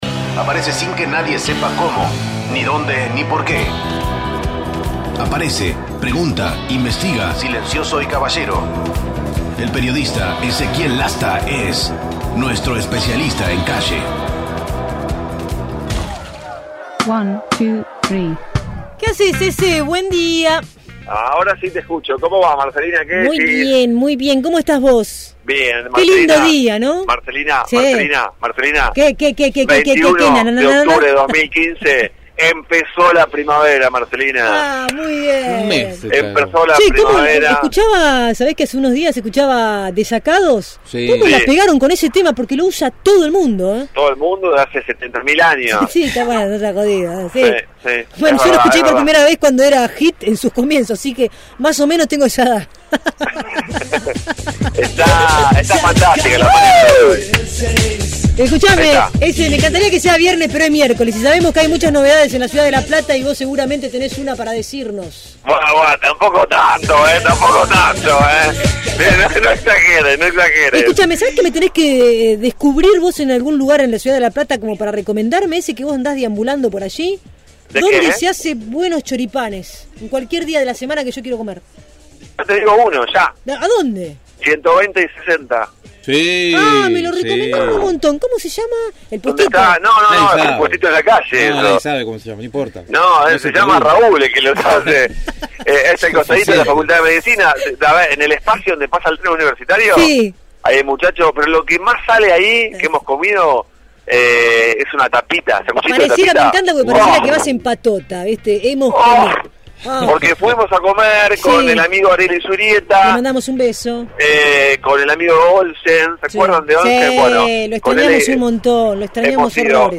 MÓVIL/ Continúa el reclamo de trabajadores del Htal. de Niños